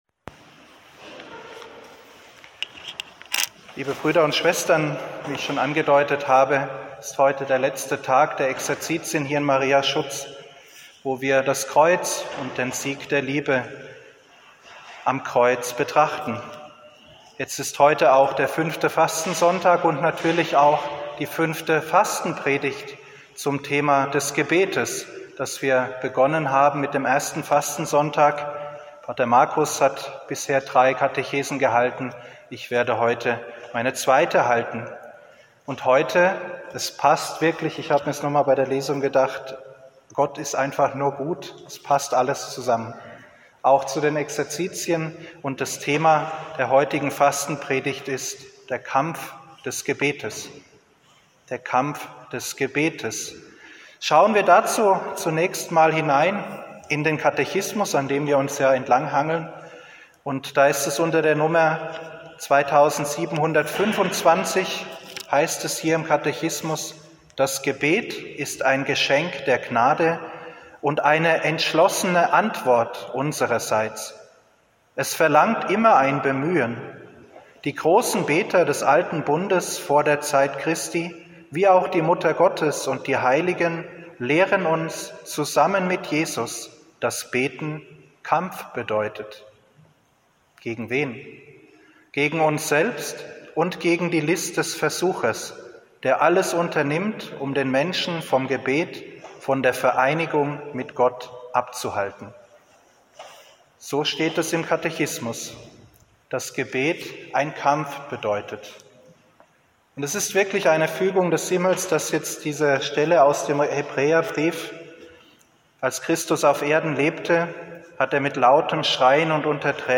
5. Fastenpredigt: Kampf des Gebets